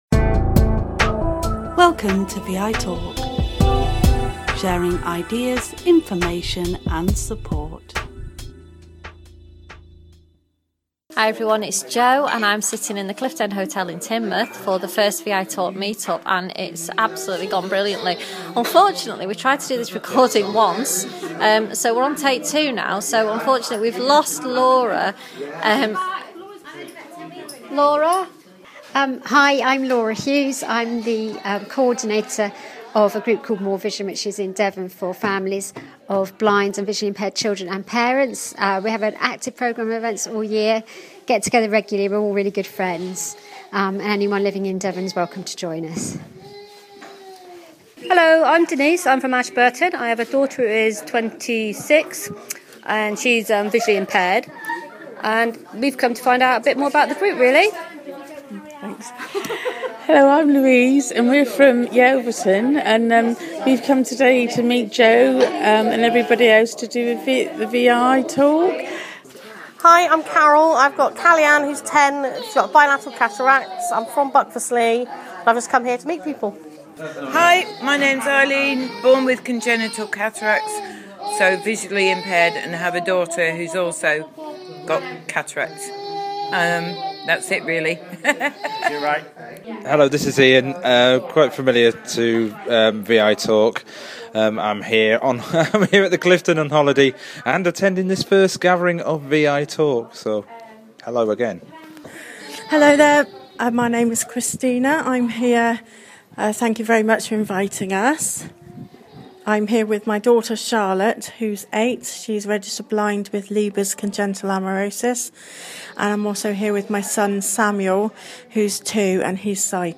This was recorded at the Cliffden Hotel Teignmough.